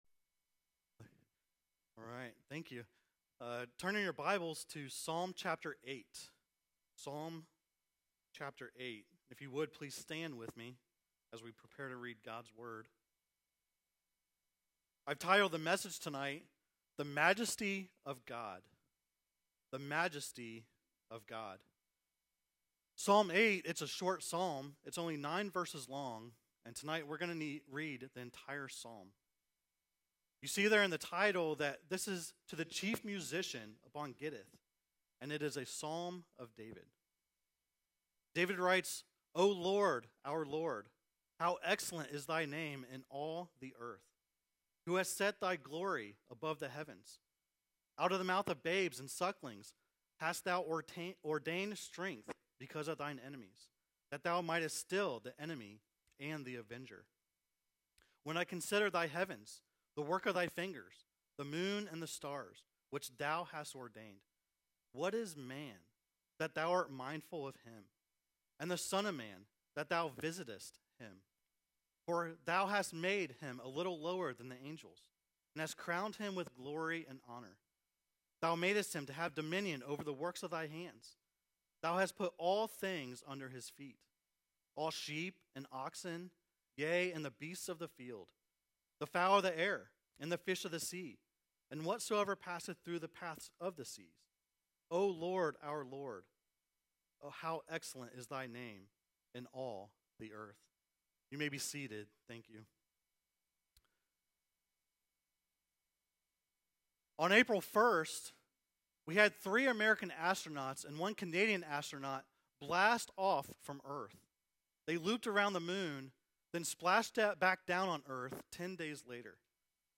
preaches from Psalm 8 on Sunday evening April 19, 2026